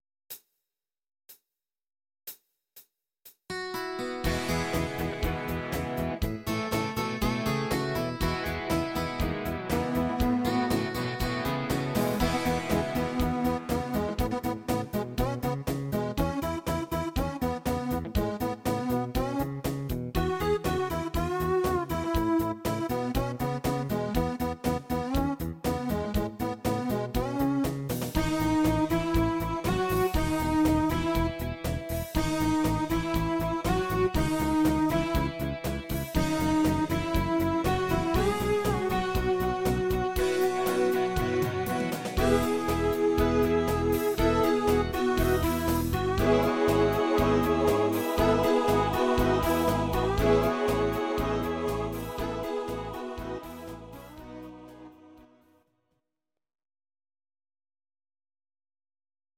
Please note: no vocals and no karaoke included.
Your-Mix: Country (822)